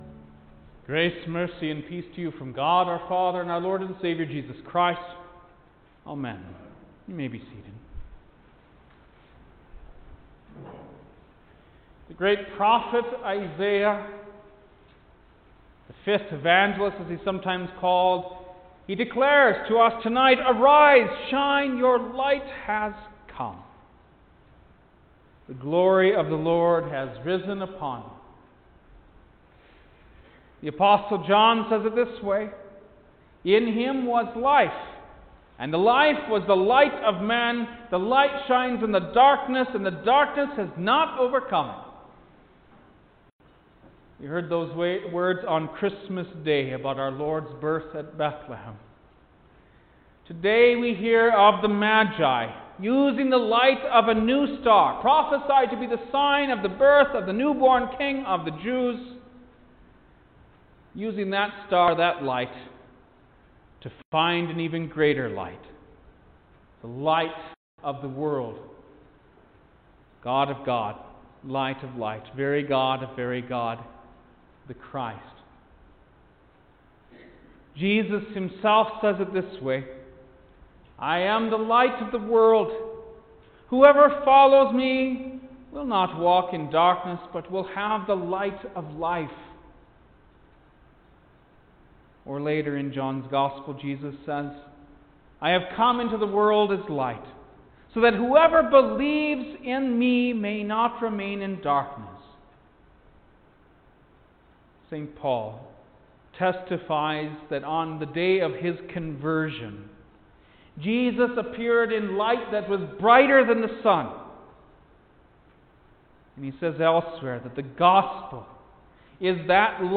January-6_2020-The-Epiphany-of-Our-Lord-sermon.mp3